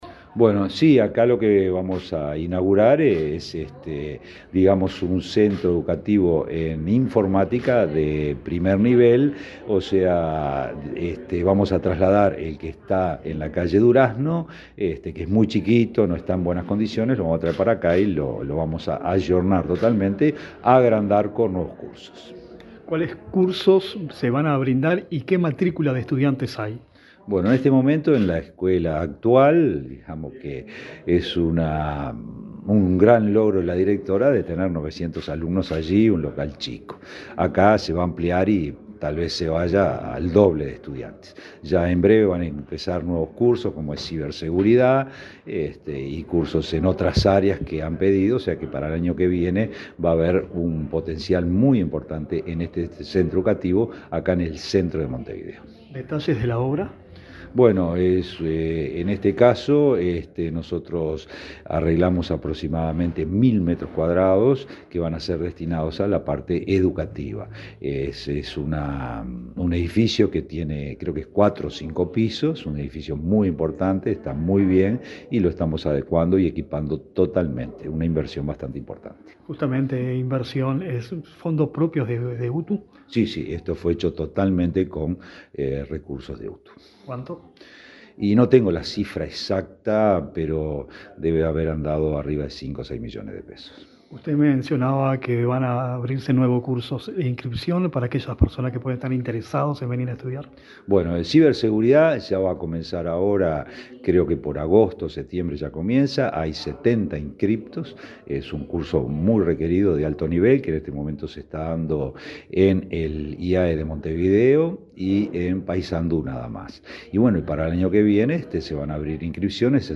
Entrevista al director general de UTU, Juan Pereyra